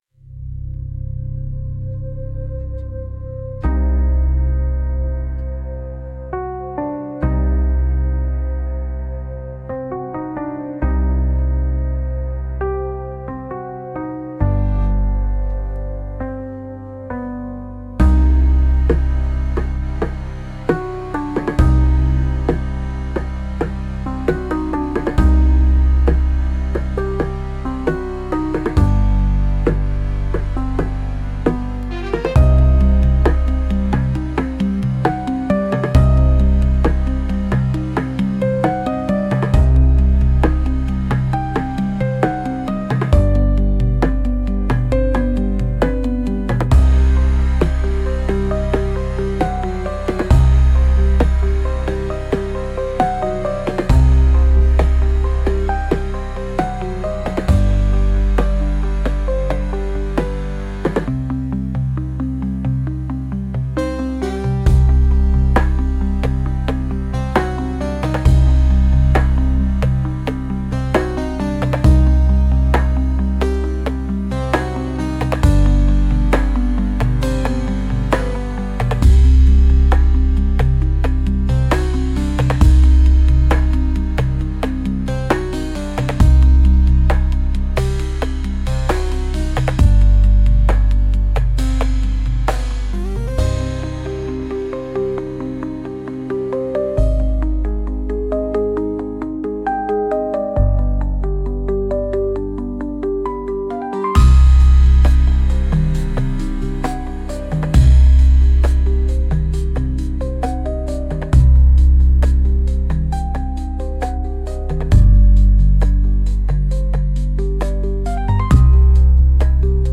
Instrumental - Antechamber of Dust - 2.34 mins